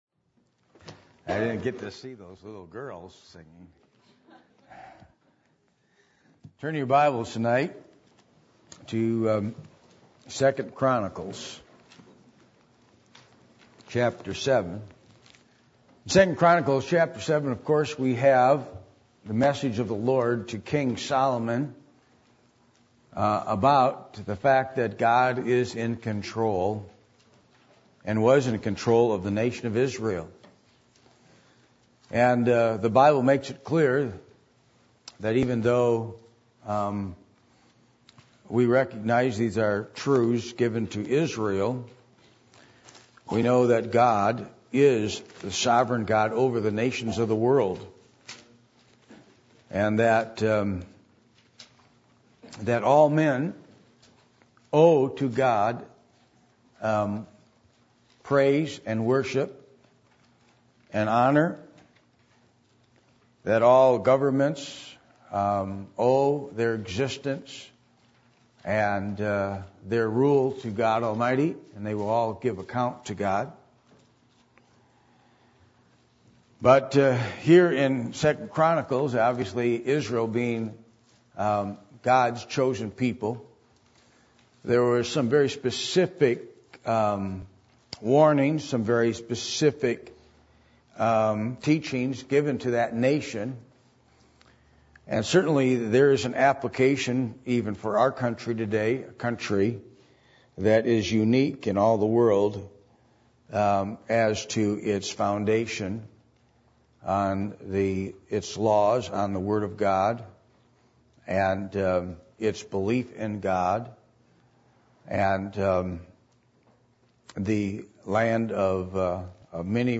Passage: 2 Chronicles 7:12-14 Service Type: Sunday Evening